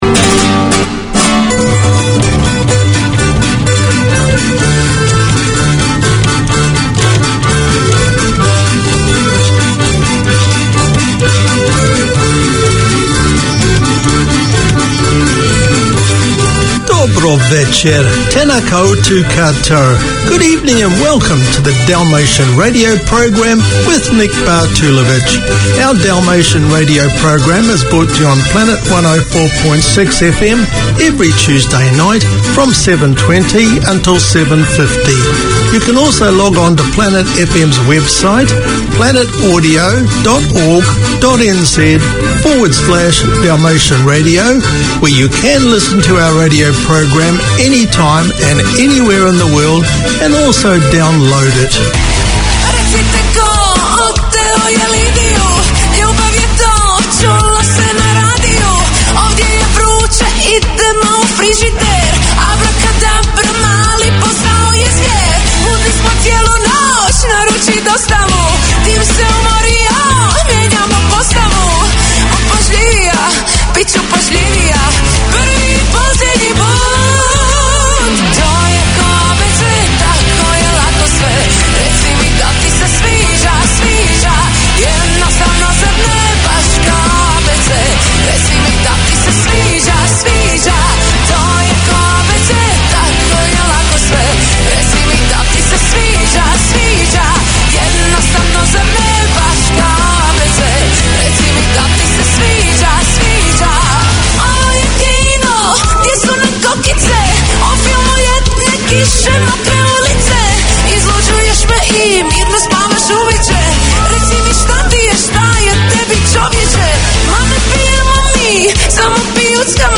Community Access Radio in your language - available for download five minutes after broadcast.
A comprehensive arts show featuring news, reviews and interviews covering all ARTS platforms: film, theatre, dance, the visual arts, books, poetry, music ... anything that is creative.